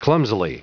Prononciation du mot : clumsily
clumsily.wav